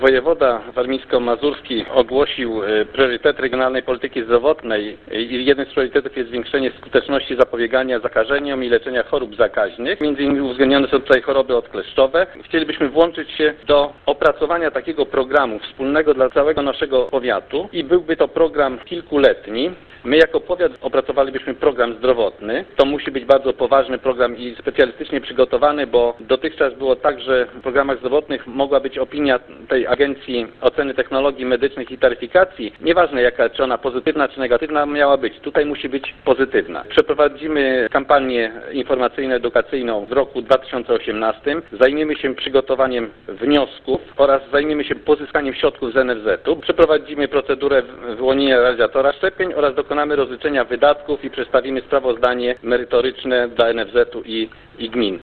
Jednak jak zaznacza w rozmowie z Radiem 5 Marian Świerszcz, starosta olecki czy program zostanie uruchomiony zależy w dużej mierze od wójtów czterech gmin leżących na terenie powiatu.